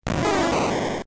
Cri de Bulbizarre K.O. dans Pokémon Diamant et Perle.